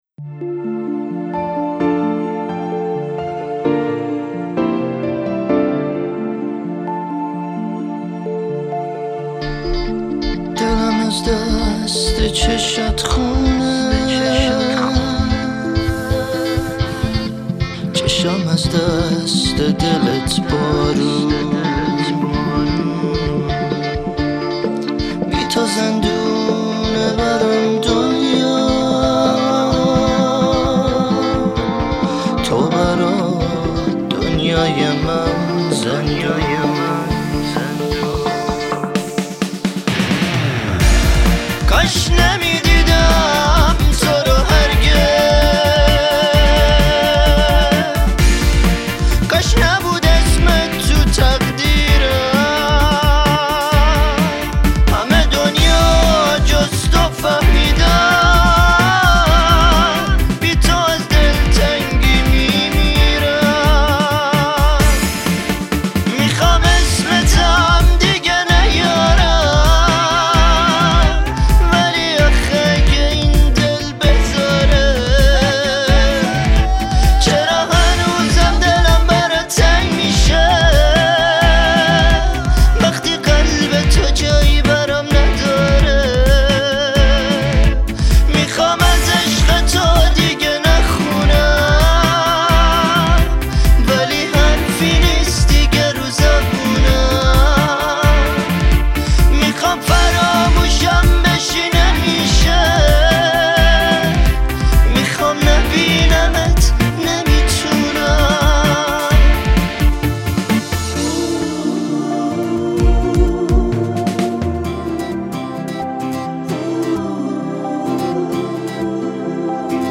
سبک راک